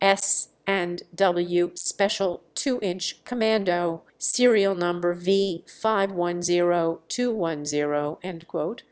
autotune_C.wav